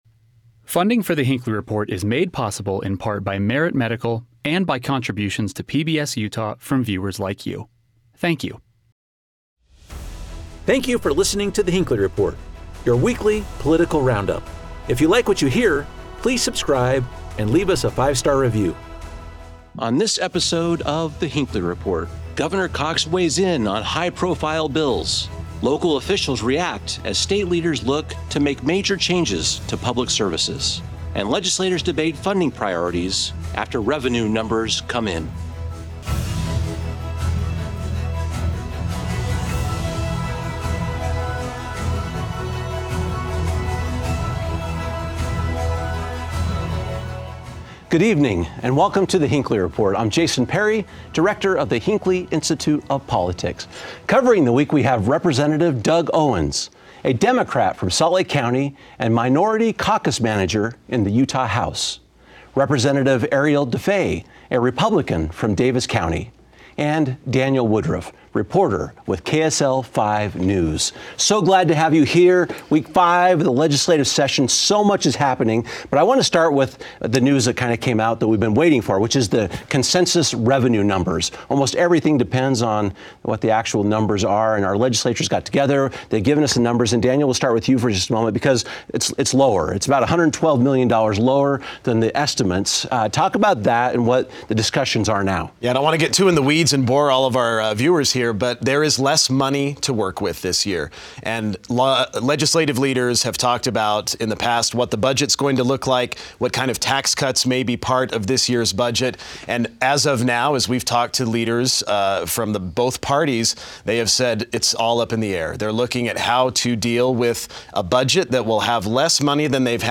Lawmakers received new estimates this week showing that projected revenue for the state is down about $112 million total. Our expert panel discusses what this means for state programs, and where cuts could be made.